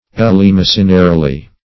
Search Result for " eleemosynarily" : The Collaborative International Dictionary of English v.0.48: Eleemosynarily \El`ee*mos"y*na*ri*ly\, adv. In an eleemosynary manner; by charity; charitably.
eleemosynarily.mp3